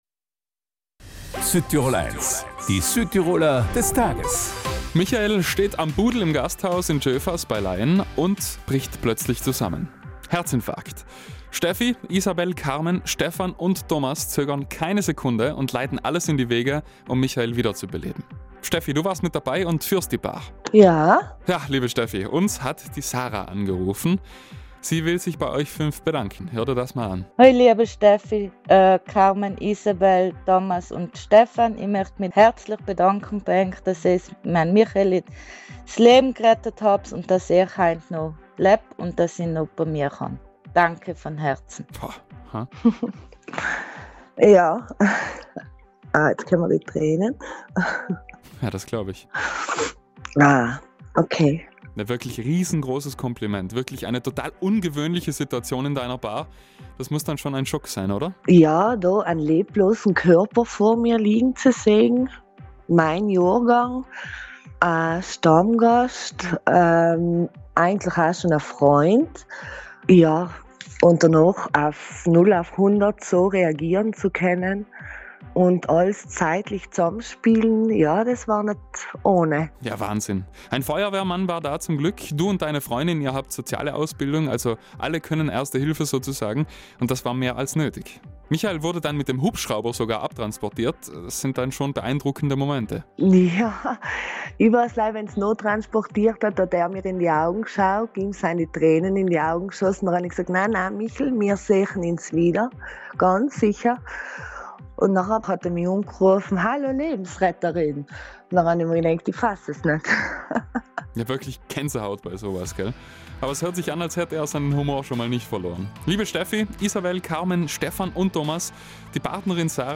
Hier das bewegende Interview zum nachhören.